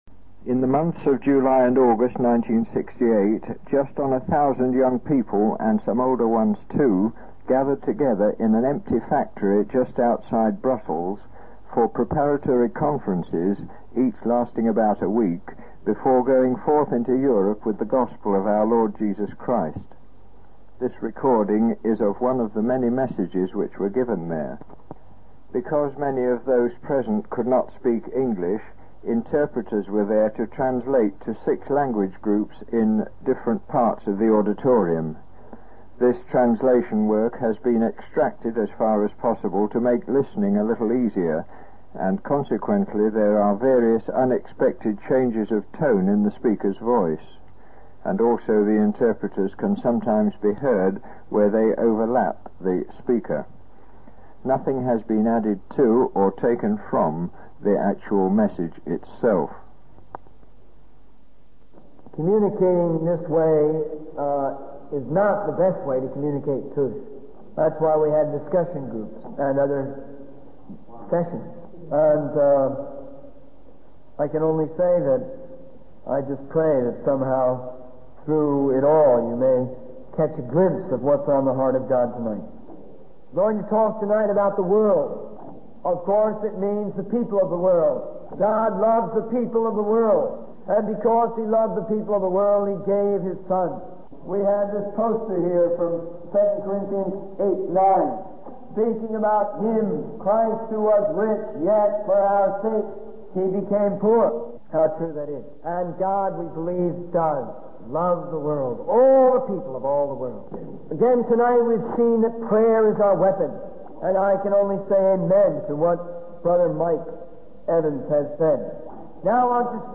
This recording is of one of the many messages which were given there. Because many of those present could not speak English, interpreters were there to translate to six language groups in different parts of the auditorium. This translation work has been extracted as far as possible to make listening a little easier, and consequently there are various unexpected changes of tone in the speaker's voice, and also the interpreters can sometimes be heard where they overlap the speaker.